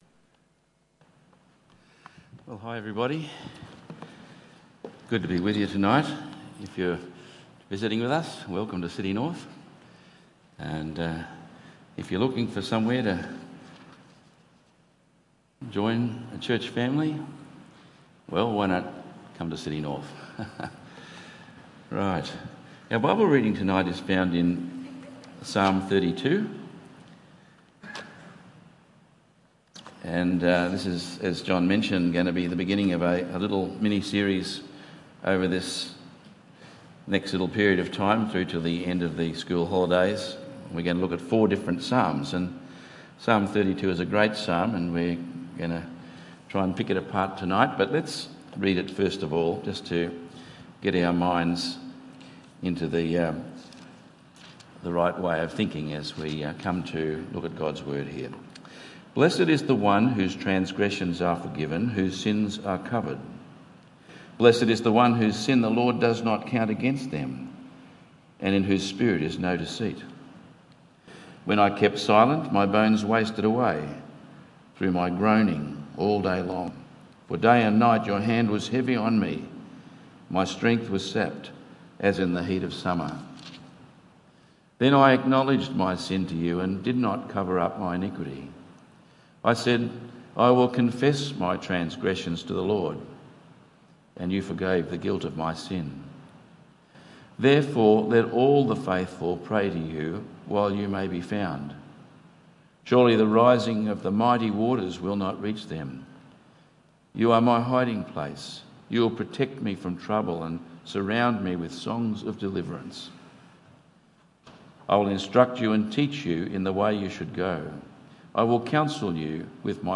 Psalm 32:1-11 Tagged with Sunday Evening